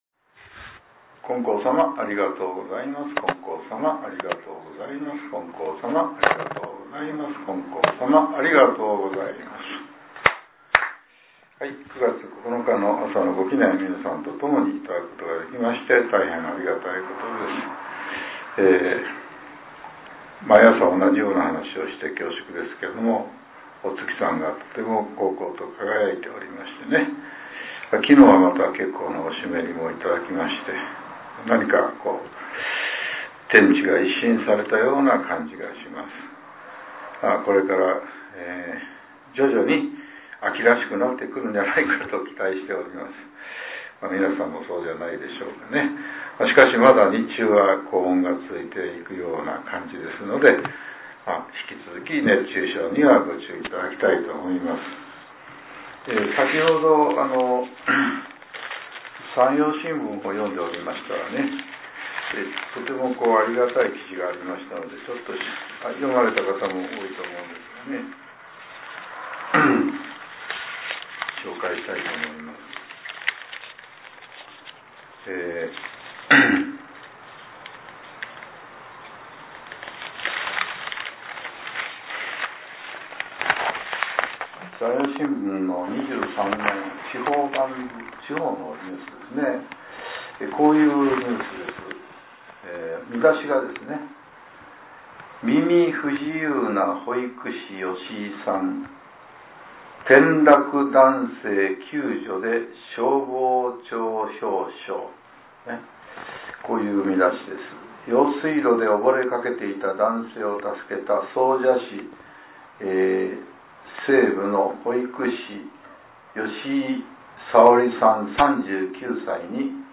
令和７年９月９日（朝）のお話が、音声ブログとして更新させれています。